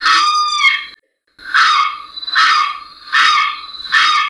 Aramus guarauna guarauna - Carao